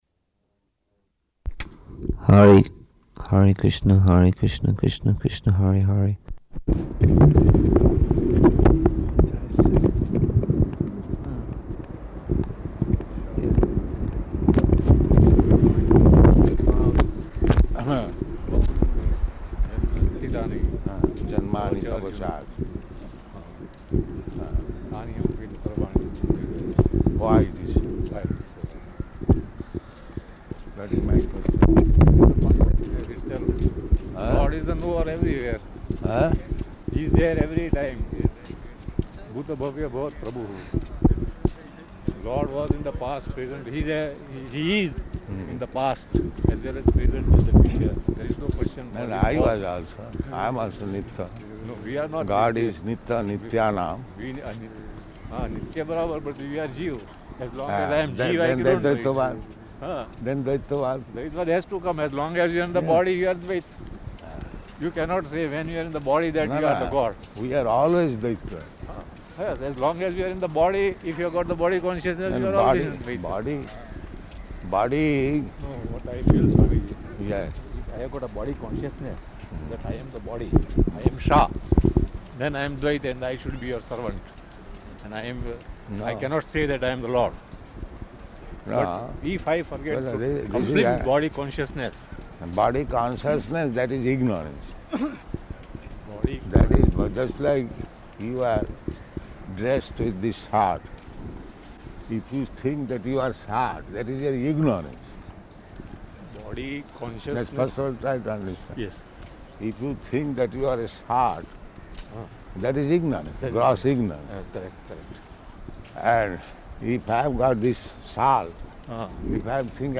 -- Type: Walk Dated: March 25th 1974 Location: Bombay Audio file